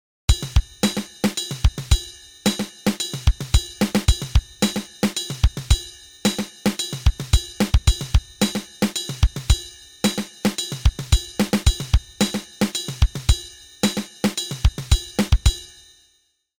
This is a feel-good, walking-around groove, a march for people with 3.5 legs. It’s airy, it’s spacey, and it turns a corner just in the way that air and space do not.
Playing odd meters slowly is a good way to get comfortable with them, as the slow tempo allows more time for counting.
This groove also sounds great with more-complex cymbal ostinatos.